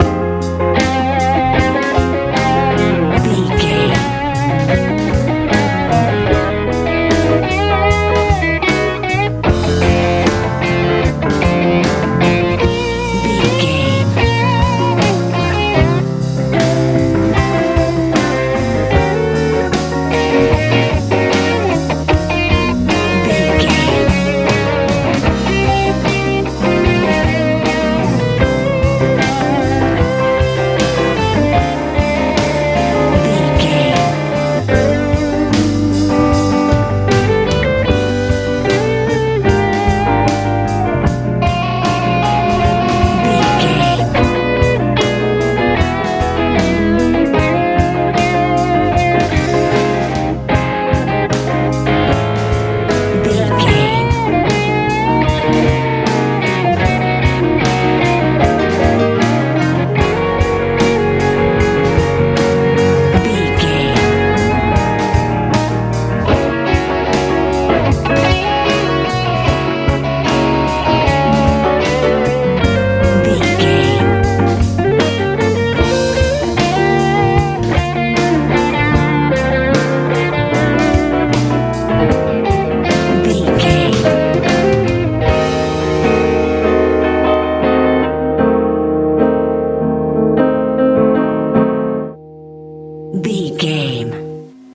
Ionian/Major
groovy
sweet
piano
electric guitar
bass guitar
drums
optimistic
hopeful